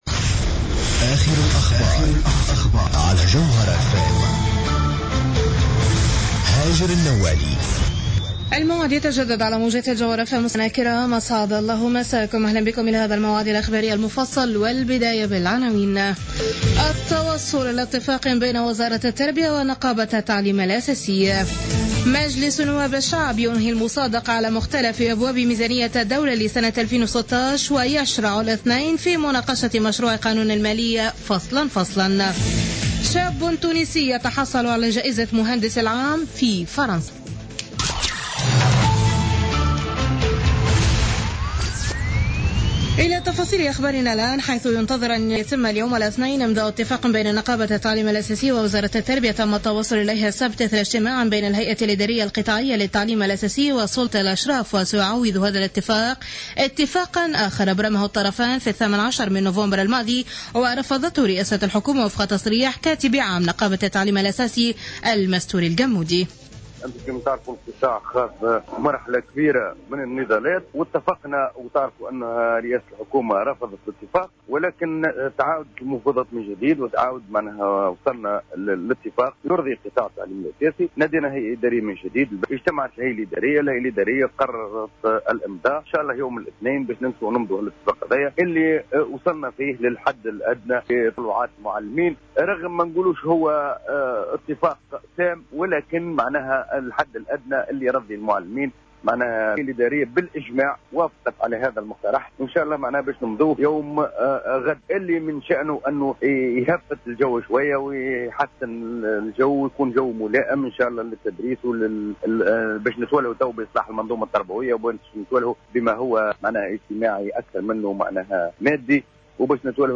نشرة أخبار منتصف الليل ليوم الإثنين 7 ديسمبر 2015